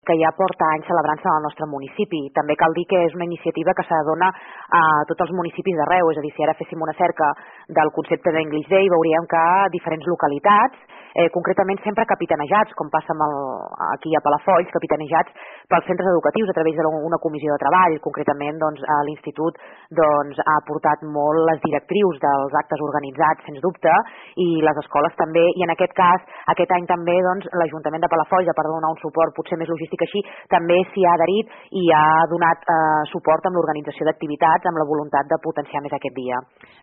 tècnica d’ensenyament.